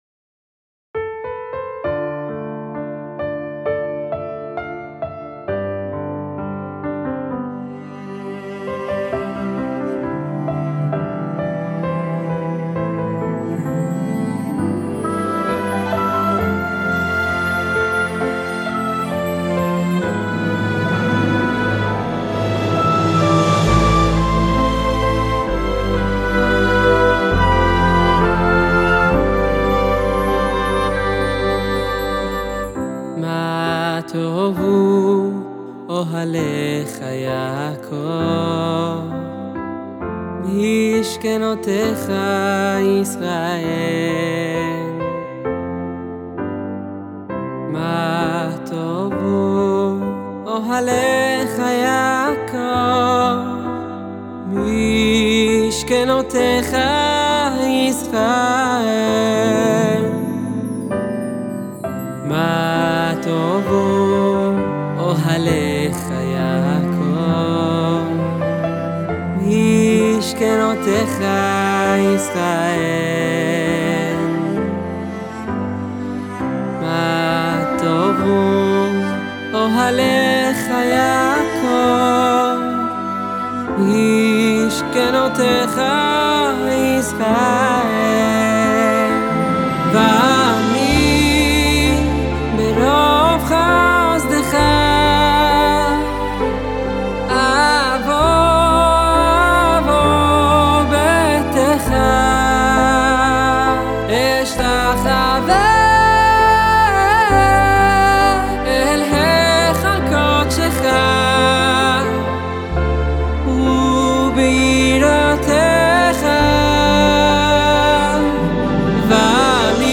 שיר החופה המרגש